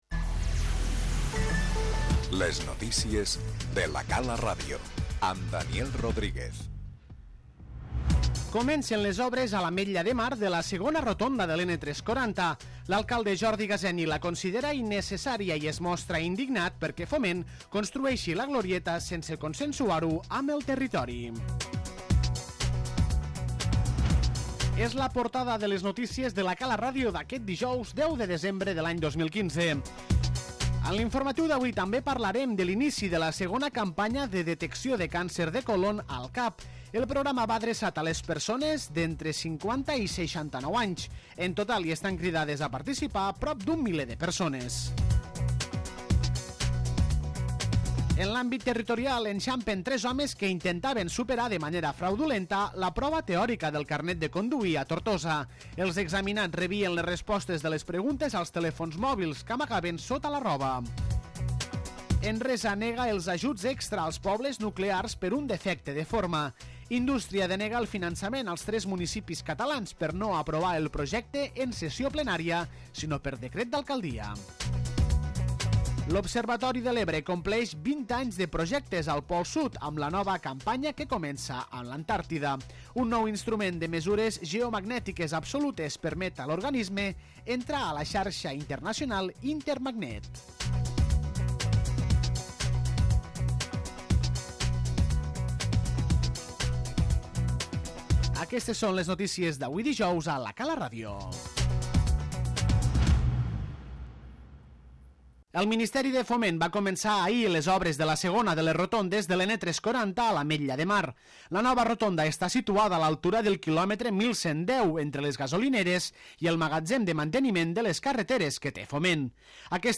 L'inici de les obres de la segona rotonda de l'N-340, a l'Ametlla de Mar, i la segona campanya de detecció de càncer de còlons centren avui l'informatiu local de La Cala Ràdio.